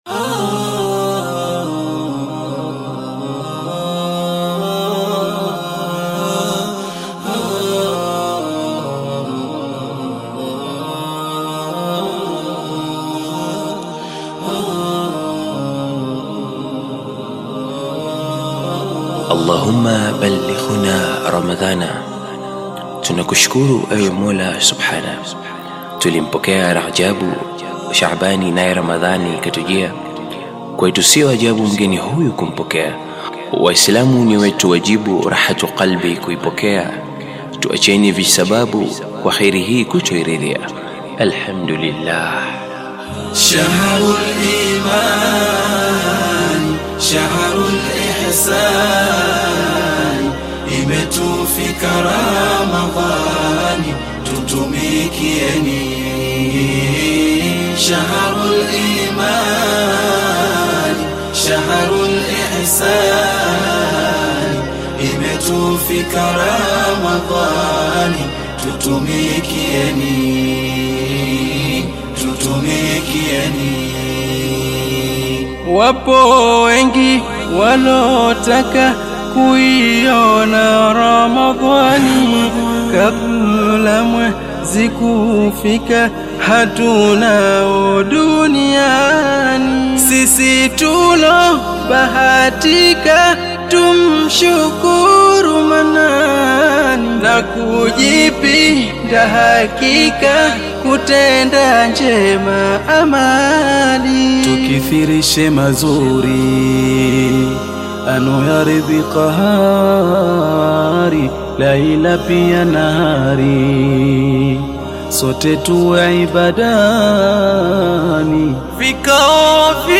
nasheed